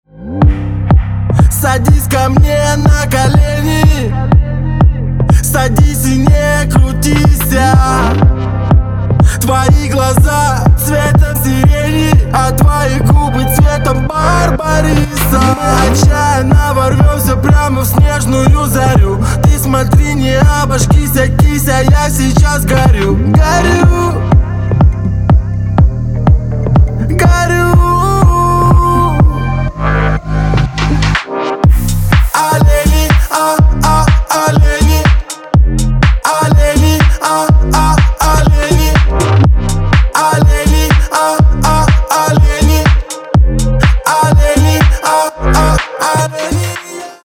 • Качество: 320, Stereo
Хип-хоп
веселые